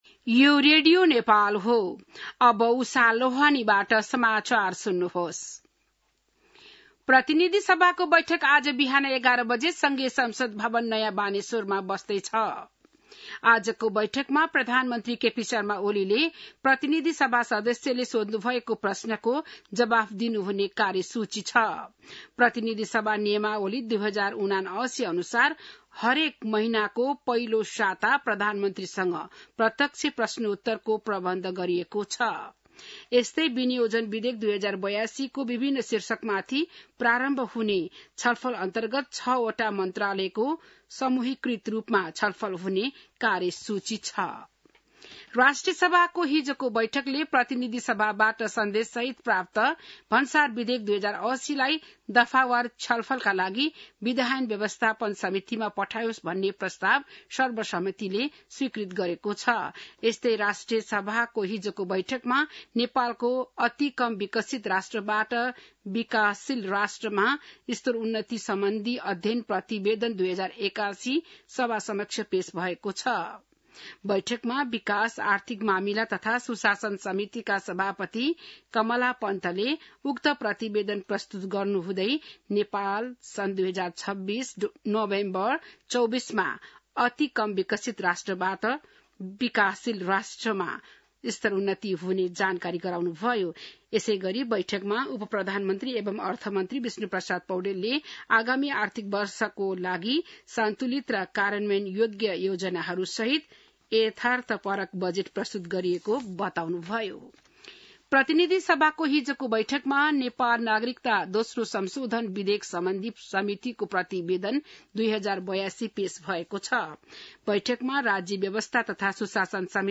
बिहान १० बजेको नेपाली समाचार : ६ असार , २०८२